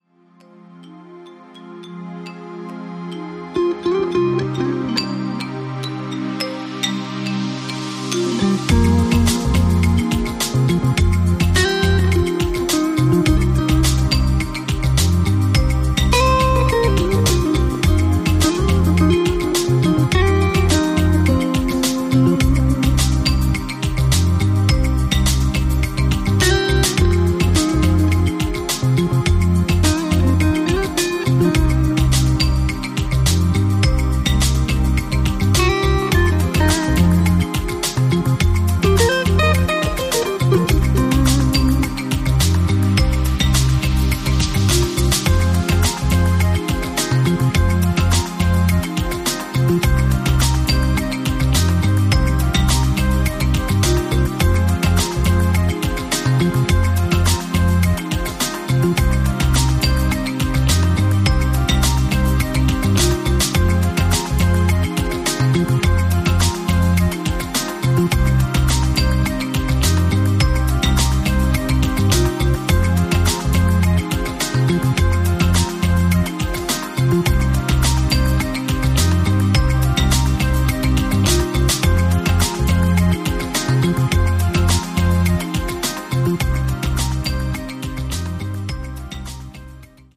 Balearic
hints of melancholy